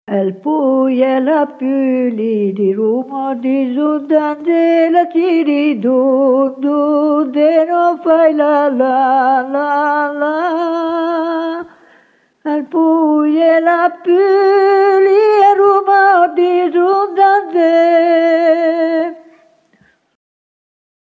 Pidocchio e pulce / [registrata a Calchesio, Sampeyre (CN), nel 1967